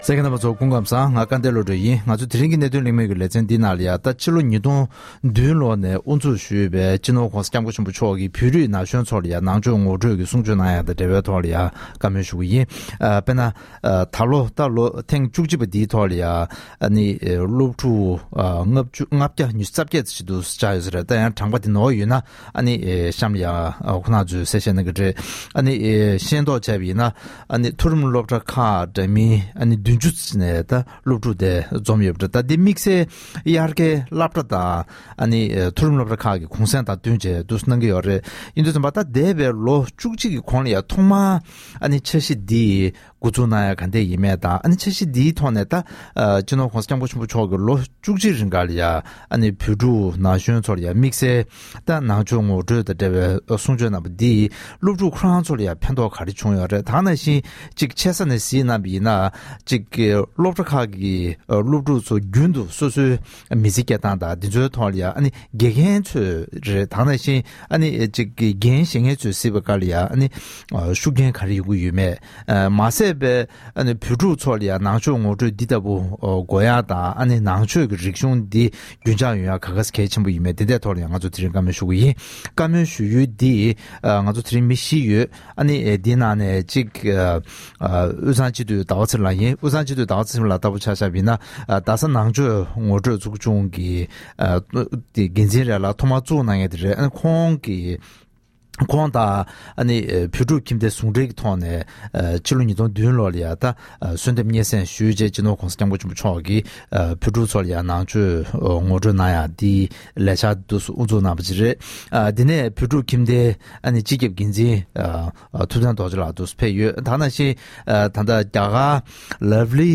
ཐེངས་འདིའི་གནད་དོན་གླེང་མོལ་གྱི་ལེ་ཚན་ནང་།